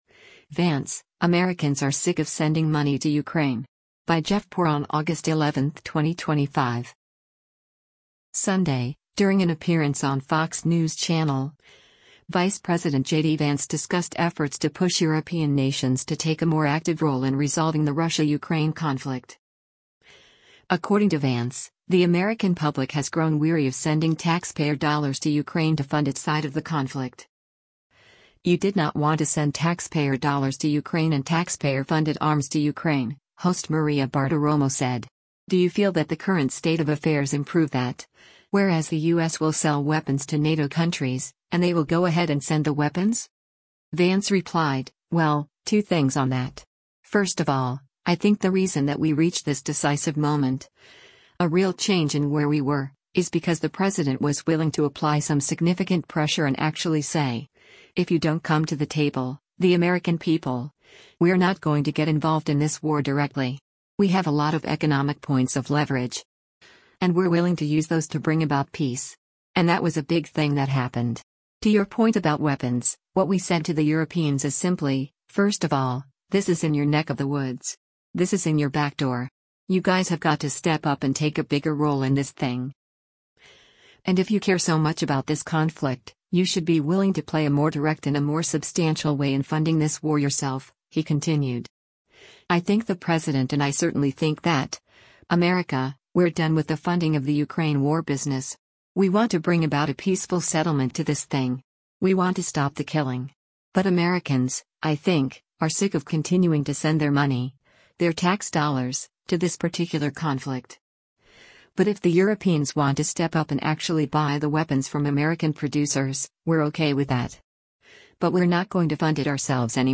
Sunday, during an appearance on Fox News Channel’s “Sunday Morning Futures,” Vice President JD Vance discussed efforts to push European nations to take a more active role in resolving the Russia-Ukraine conflict.